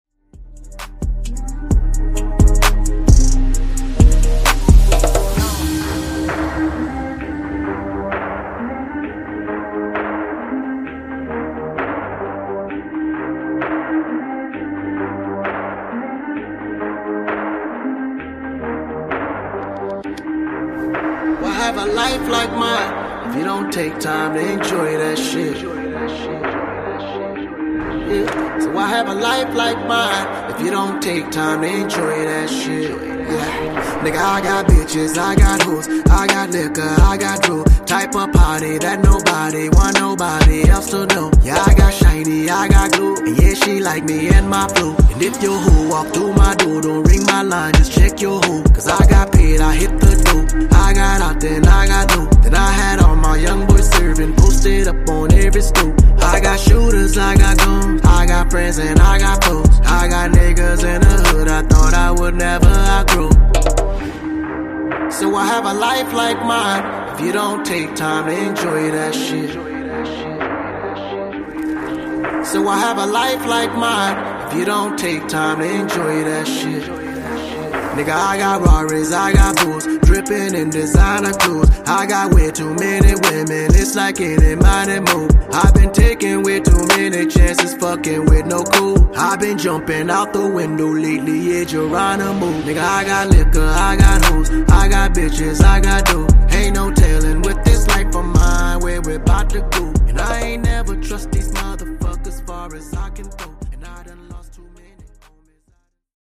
Genre: RE-DRUM Version: Dirty BPM: 120 Time